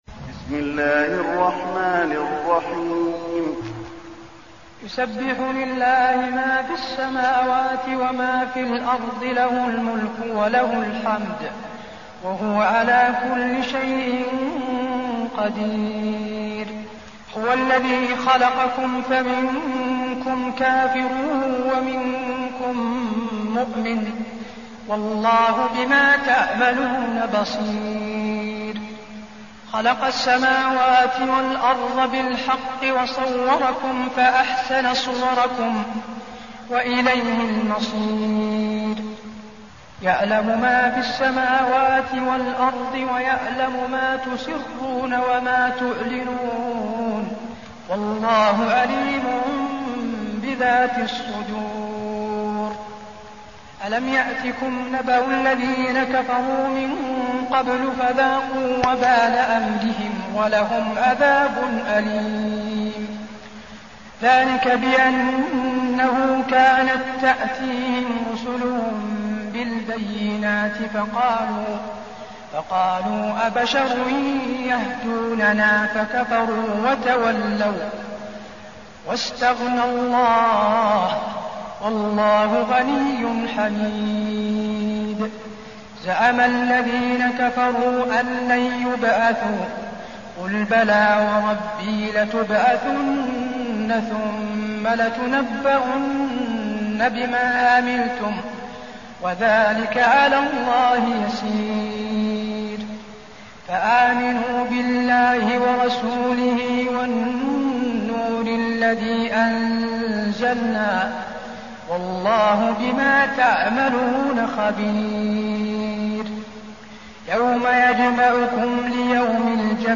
المكان: المسجد النبوي التغابن